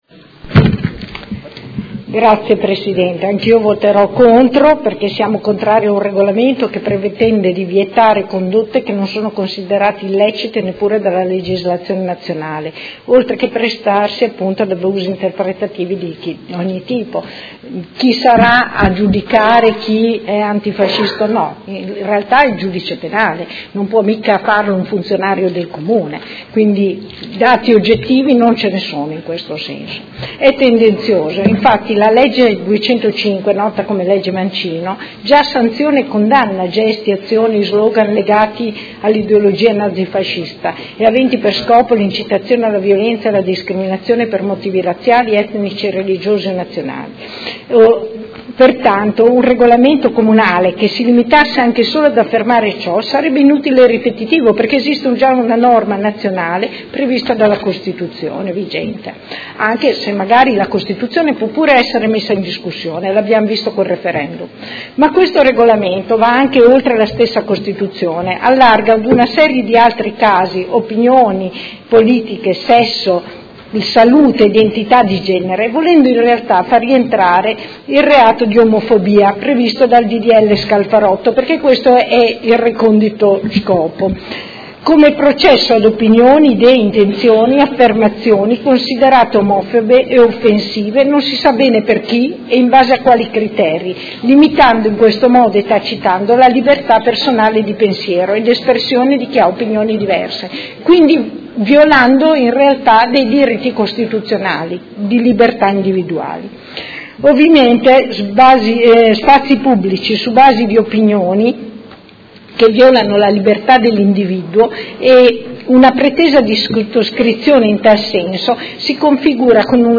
Seduta del 24/01/2019. Dichiarazioni di voto su proposta di deliberazione: Concessione di spazi pubblici e di sale - Riferimento al rispetto della Costituzione della Repubblica Italiana e in particolare ai principi e valori della Resistenza e dell'antifascismo e alla condanna ad ogni forma di discriminazione – Modifica del Regolamento comunale per l'applicazione della tassa per l'occupazione di spazi ed aree pubbliche e per il rilascio delle concessioni di suolo pubblico